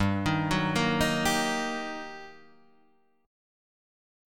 GmM7#5 Chord